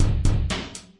Drum Percussion Loops " Drum Loop Rock01 120
Tag: 回路 命中 岩石 节奏 冲击 120-BPM 打击乐器 击败 打击乐器环 量化 鼓环 常规